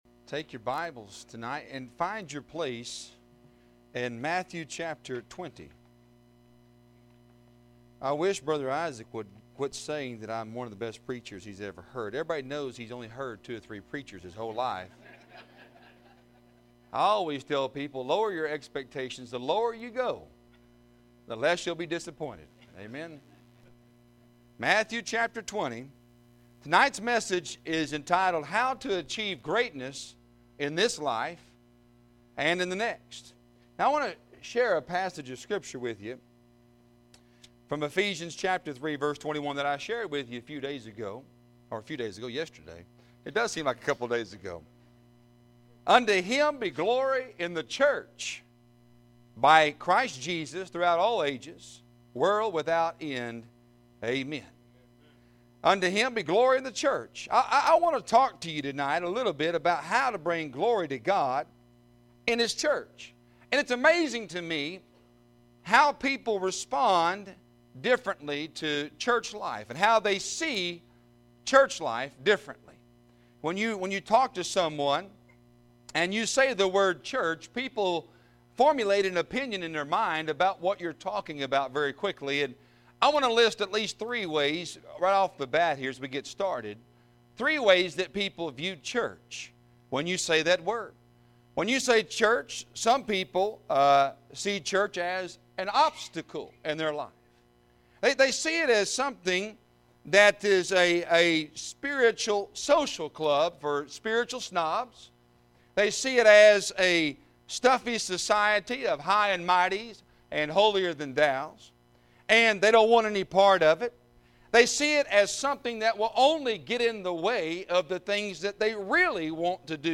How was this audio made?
Revival 2021 Service 3 – How To Achieve Greatness in This Life and the Next